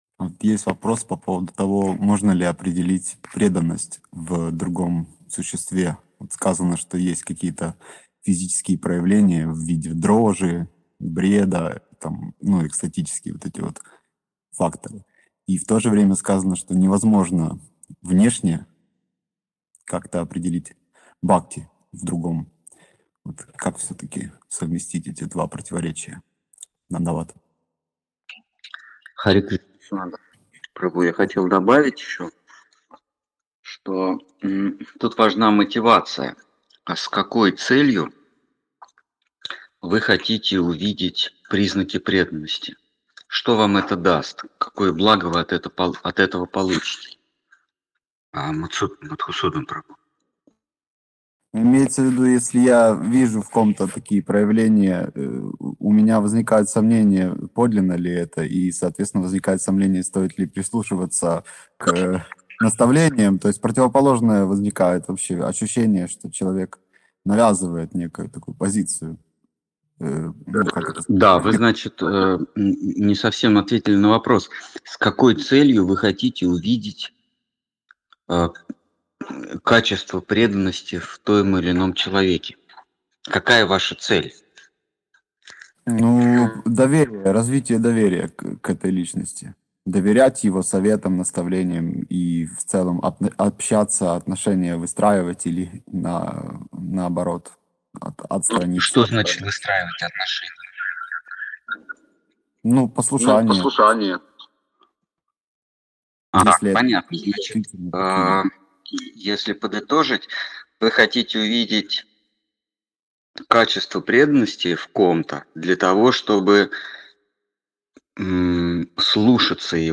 Ответы на вопросы из трансляции в телеграм канале «Колесница Джаганнатха».